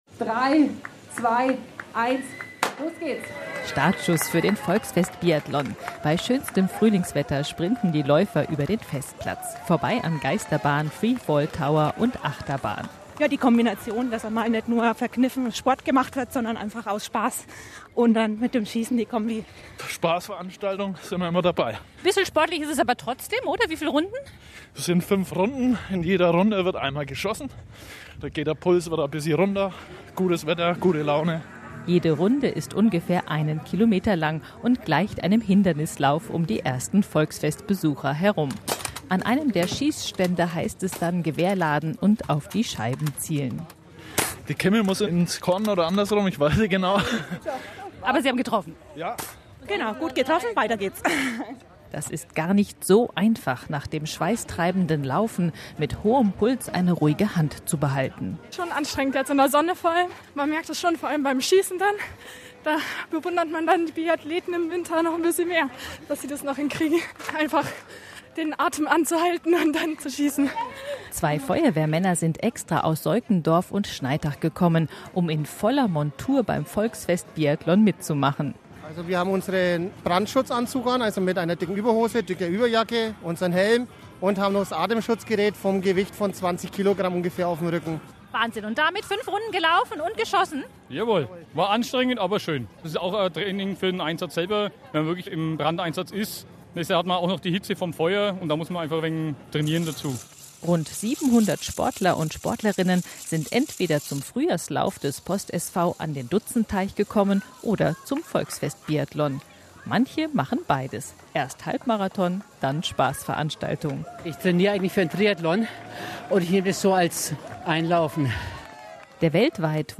Volksfest-Biathlon-2018-Interview-Bayern-1.mp3